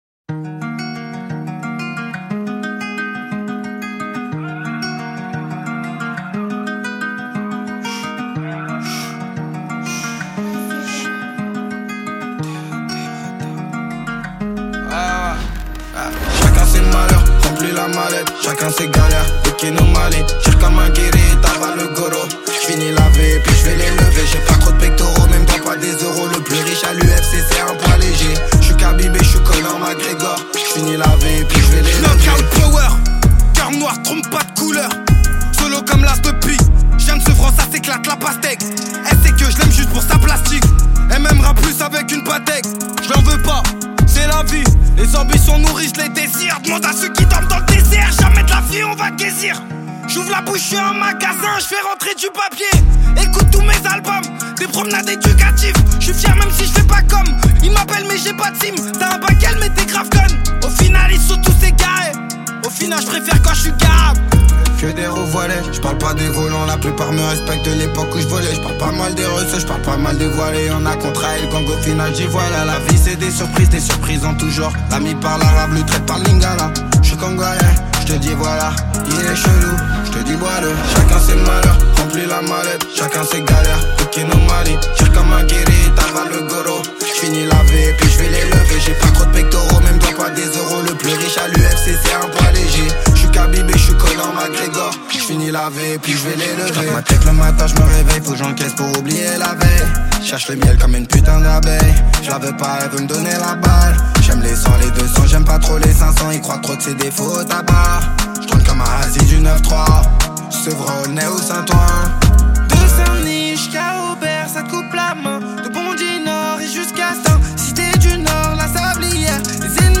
french rap Télécharger